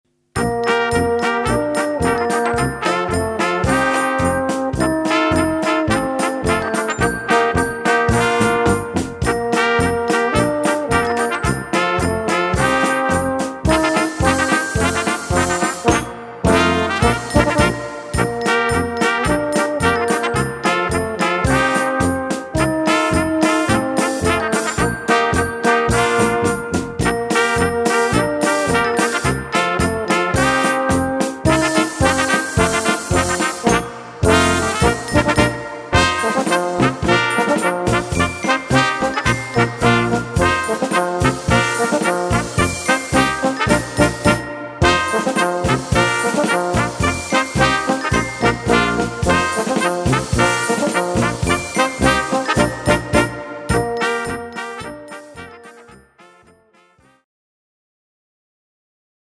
4-stimmig, variable Besetzung mit Percussion
Besetzung: Ensemble gemischt